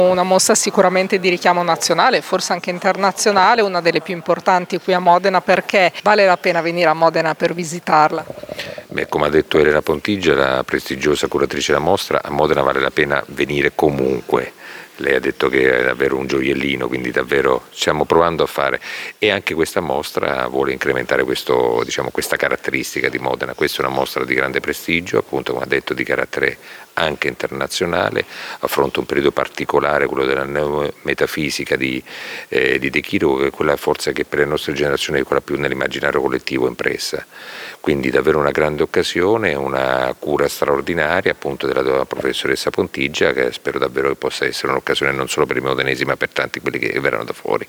Il sindaco di Modena, Massimo Mezzetti: